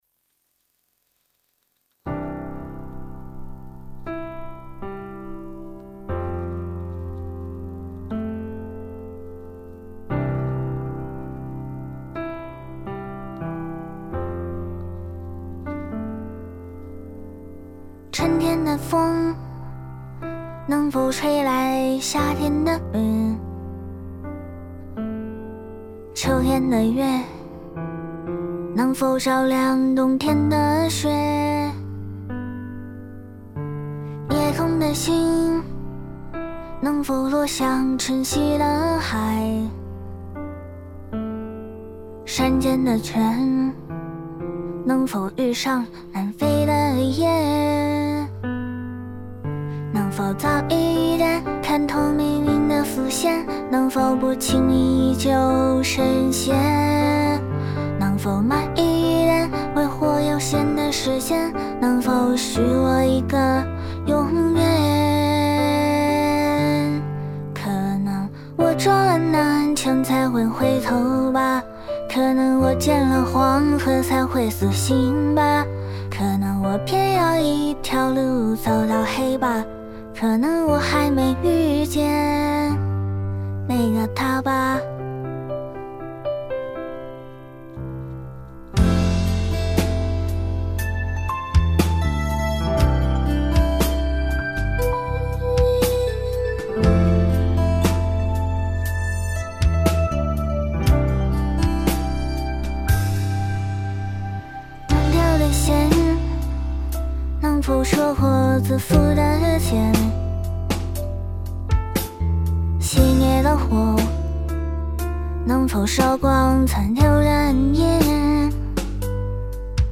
推理效果试听
# 女声# 适合文本朗读模型# 游戏角色# 声优# 可爱声音